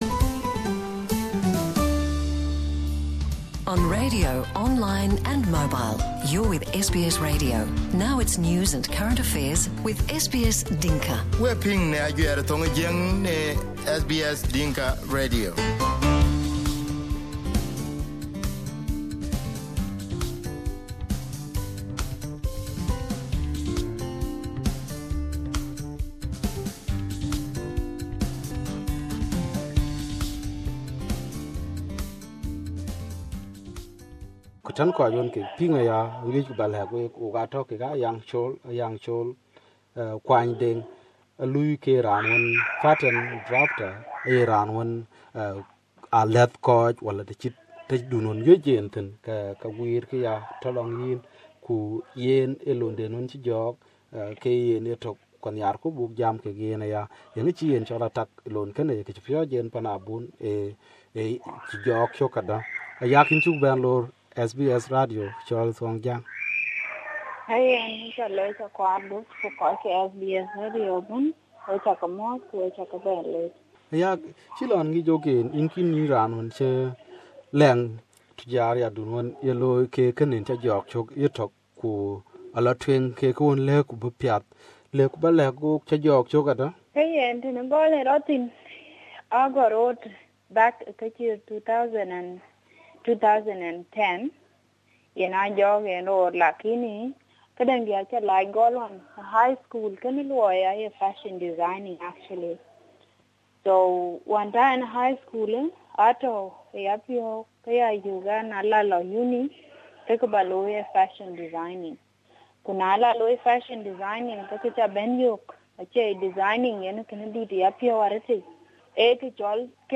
This is an interview with the most out spoken young women